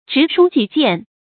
直抒己見 注音： ㄓㄧˊ ㄕㄨ ㄐㄧˇ ㄐㄧㄢˋ 讀音讀法： 意思解釋： 直：直爽；抒：抒發；表達。